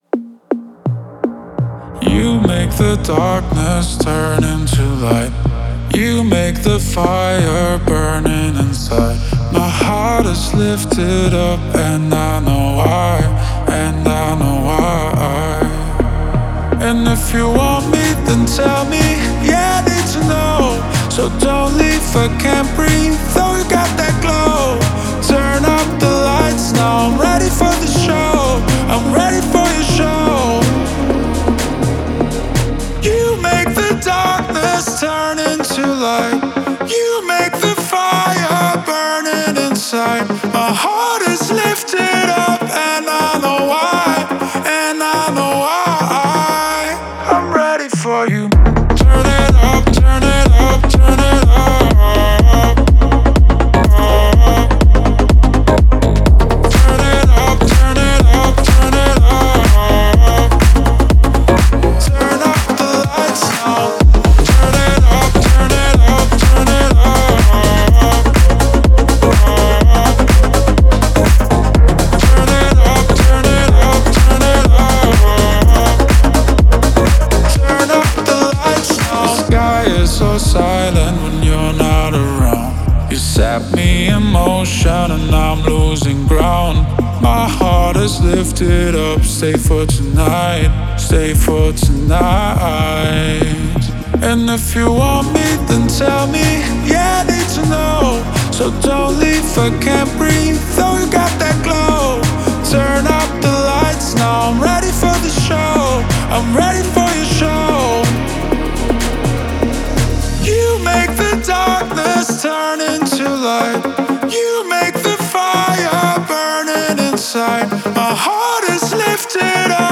динамичная танцевальная композиция